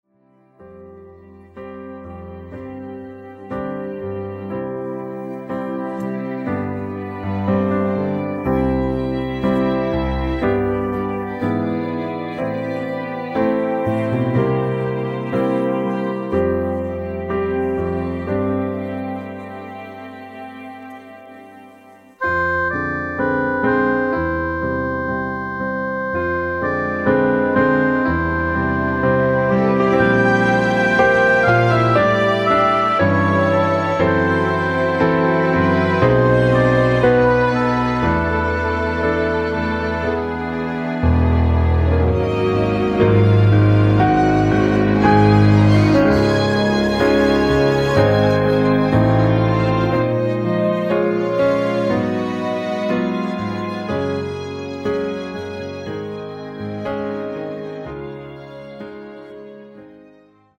음정 원키 3:10
장르 가요 구분 Voice Cut